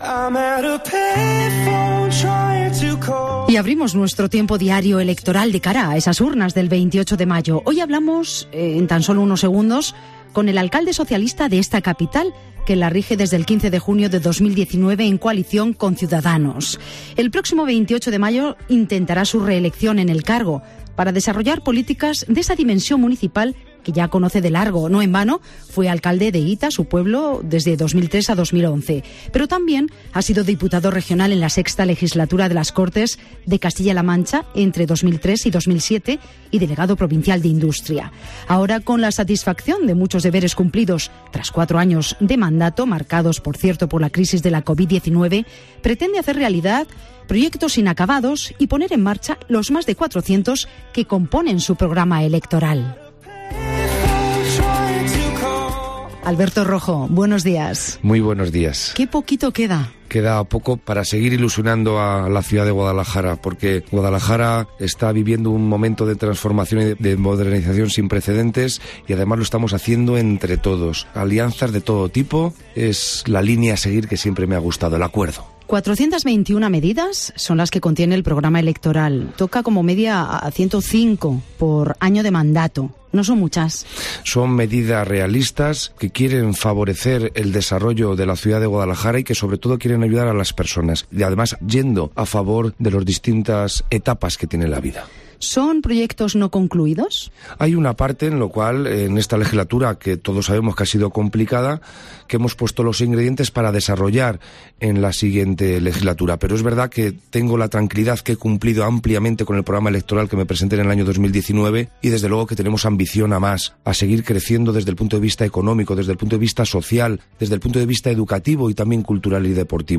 El candidato a la reelección como alcalde de la capital ha abordado en Herrera en COPE Guadalajara parte de su programa electoral, compuesto por 421 medidas
En la recta final de la campaña electoral de cara a las urnas locales y autonómicas del próximo 28 de mayo, el alcalde de Guadalajara y candidato a la reelección por el PSOE, Alberto Rojo, ha pasado por los micrófonos de 'Herrera en COPE Guadalajara' para exponer algunas de las principales propuestas de su programa electoral.